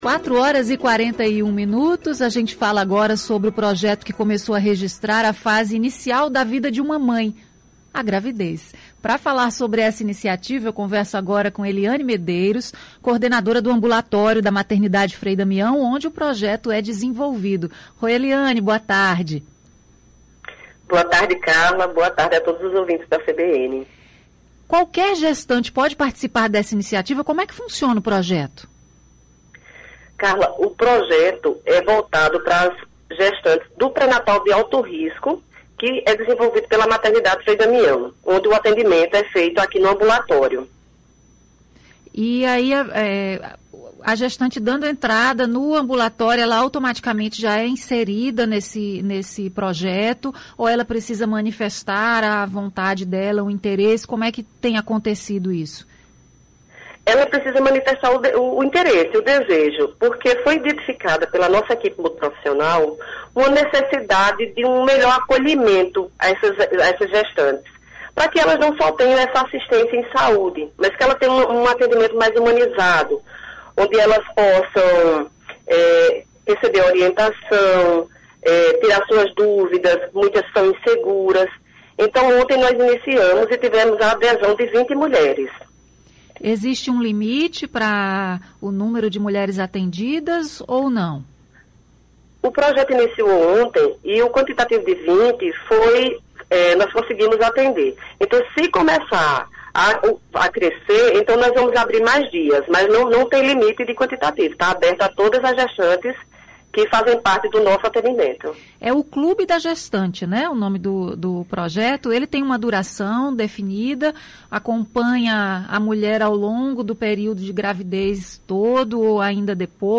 Entrevista: fotografia de mulheres gestantes